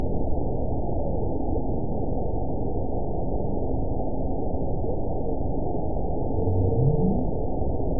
event 921734 date 12/18/24 time 00:46:11 GMT (11 months, 2 weeks ago) score 9.07 location TSS-AB02 detected by nrw target species NRW annotations +NRW Spectrogram: Frequency (kHz) vs. Time (s) audio not available .wav